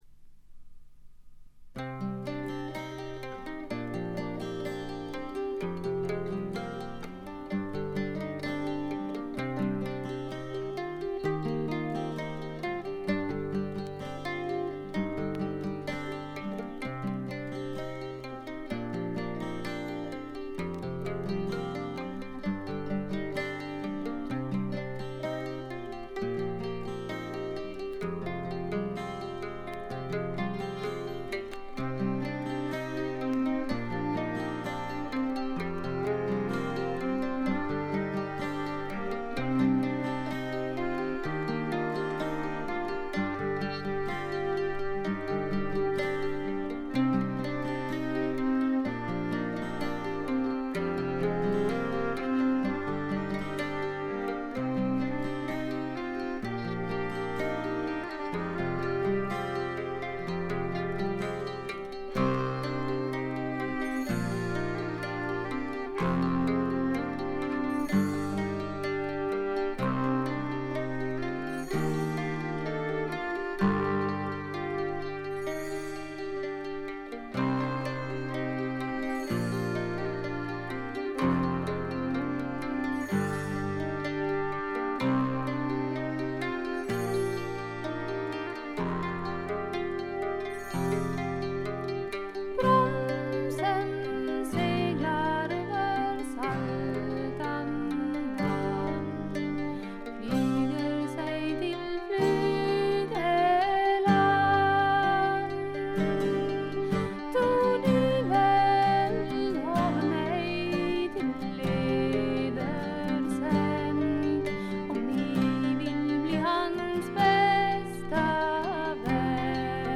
軽微なチリプチ少し。
スウェーデンのトラッド・フォーク・グループ。
試聴曲は現品からの取り込み音源です。
Recorded At - Metronome Studio, Stockholm